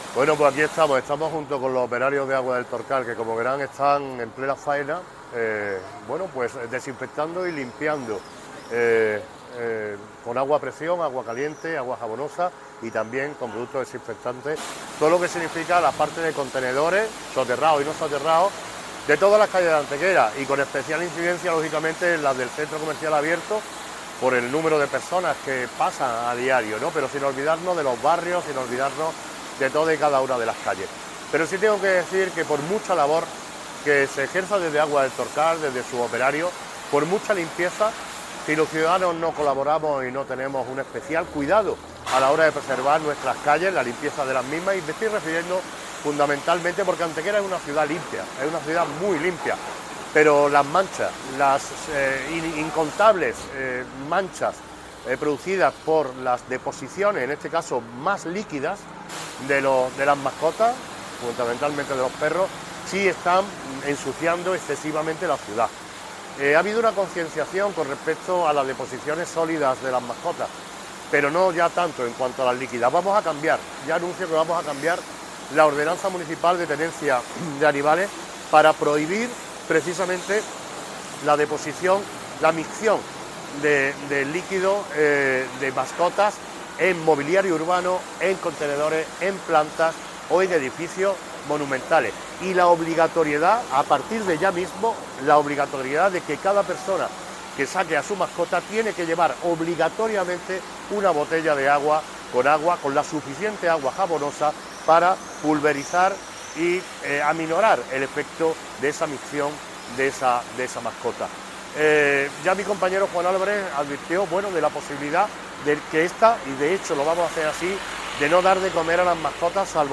El alcalde de Antequera, Manolo Barón, y el teniente de alcalde delegado de Aguas del Torcal, Antonio García, informan de la puesta en marcha de una campaña especial de limpieza, baldeo y desinfección de contenedores de residuos ubicados en vías públicas así como de sus alrededores, tratando así de mitigar los inconvenientes que ocasiona la llegada del verano en cuanto a altas temperaturas y ausencia de lluvias se refiere.
Cortes de voz